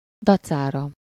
Ääntäminen
IPA : /dɪˈspaɪt/